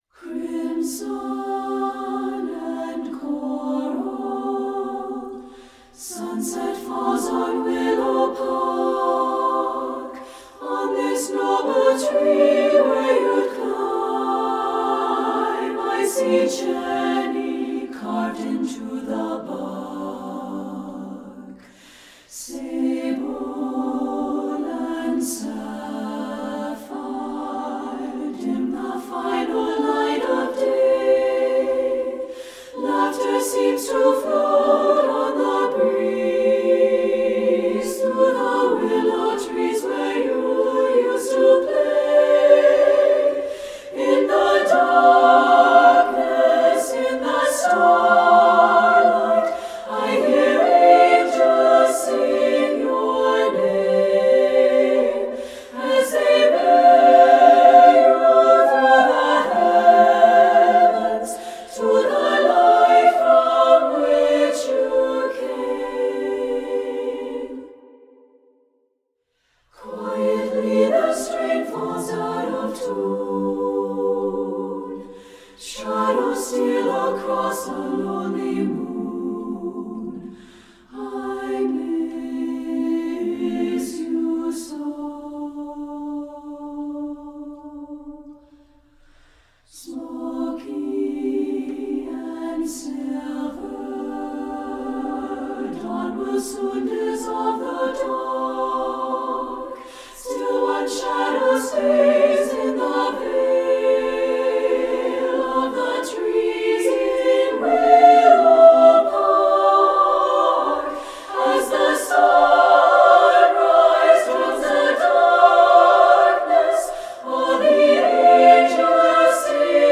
Choral Concert/General Women's Chorus
SSAA A Cap